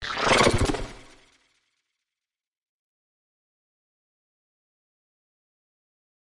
标签： electric lofi sounddesign soundeffect sounddesign future delay effect abstract fx sfx scifi sound
声道立体声